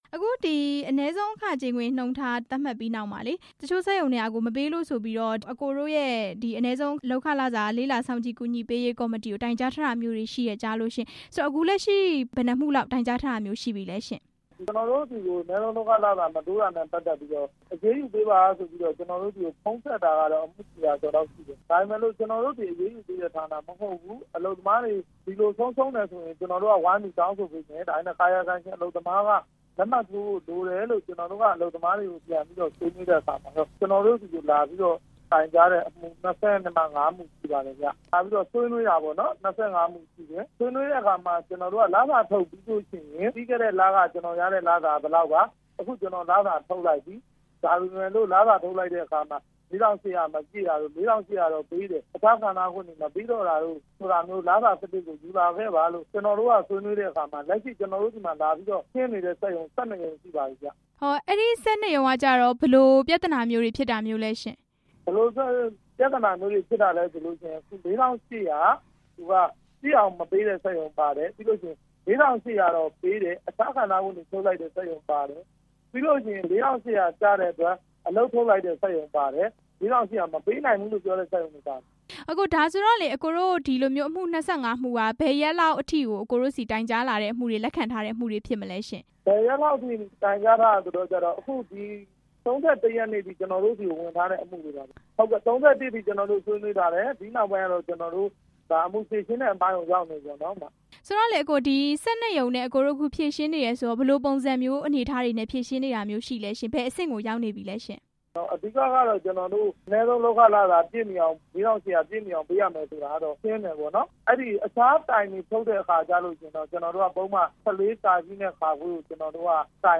အနည်းဆုံးလုပ်ခမပေးတဲ့ စက်ရုံတွေကို တိုင်ကြားမှု အကြောင်း မေးမြန်းချက်